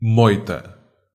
Moita (pronounced [ˈmojtɐ]
Pt-pt_Moita_FF.ogg.mp3